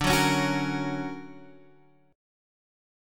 D+M7 chord